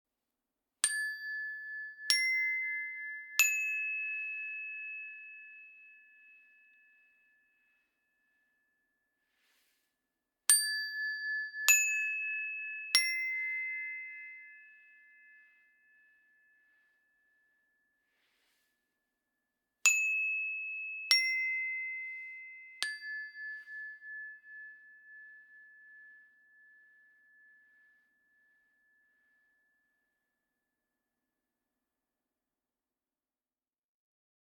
Meinl Sonic Energy Three Tone Energy Chime - Moll Dreiklang/432 Hz (ECTT1)